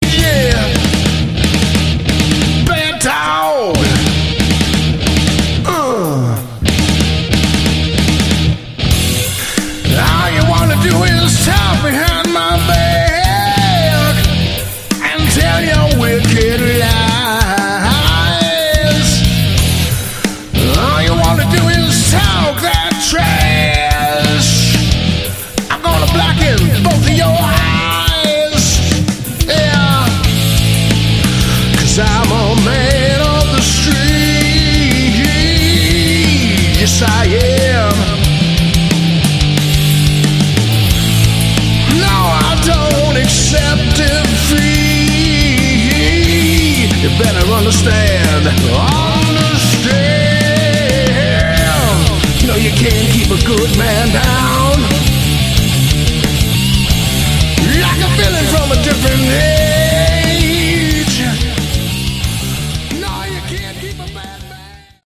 Category: Hard Rock
guitars
vocals